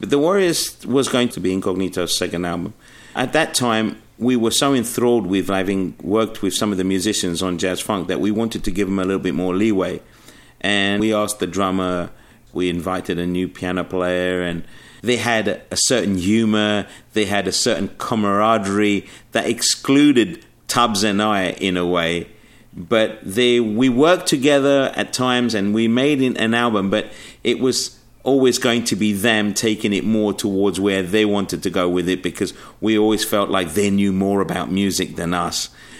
Exclusive on Audioboom, Bluey from Incognito chats about the origins of his follow up to the "Jazz Funk" album, with a more jazzy edge